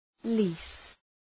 Shkrimi fonetik {li:s}
lease.mp3